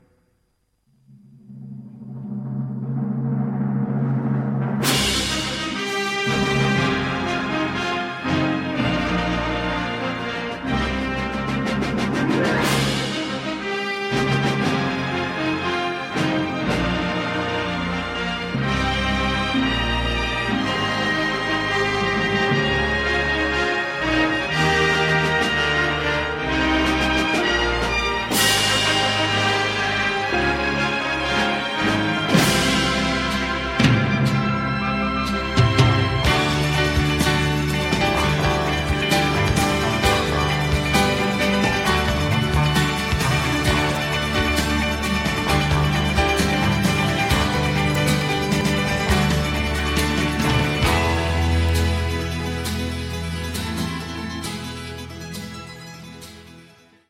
the third studio album